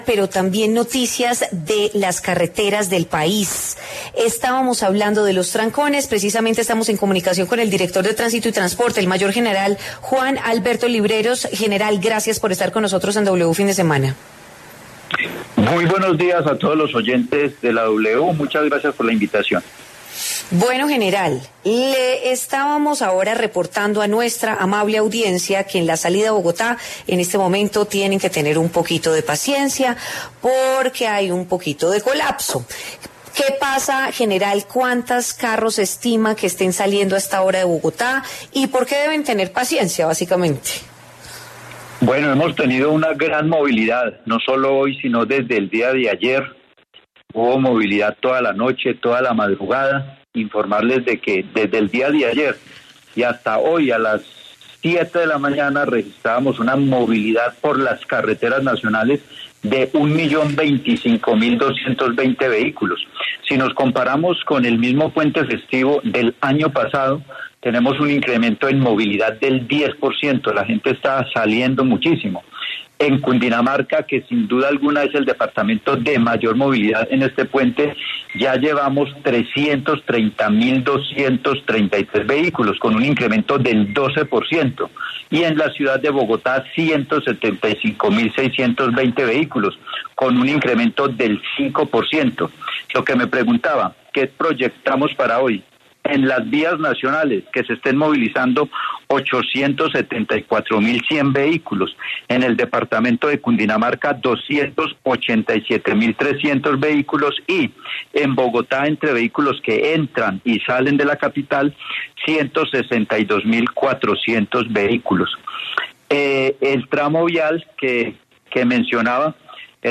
El director de la Policía de Tránsito y Transporte, el mayor general Juan Alberto Libreros, habló en W Fin de Semana sobre el estado de las principales vías del país.